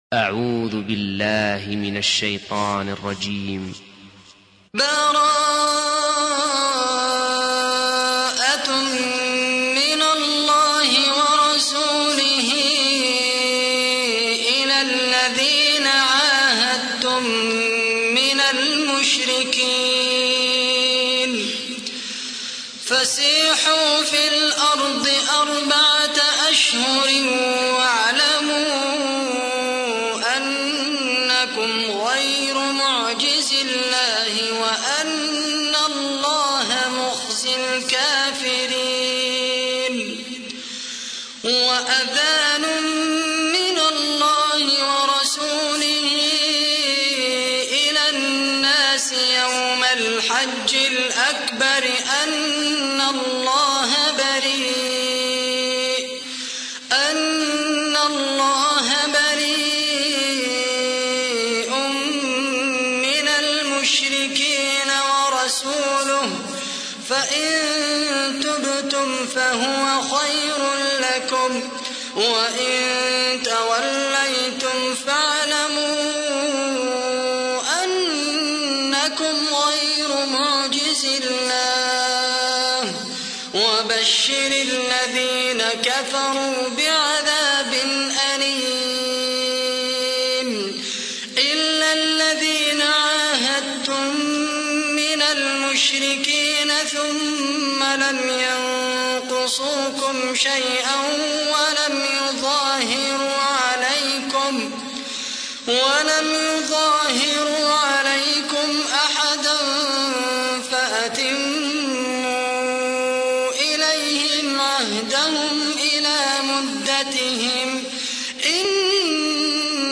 تحميل : 9. سورة التوبة / القارئ خالد القحطاني / القرآن الكريم / موقع يا حسين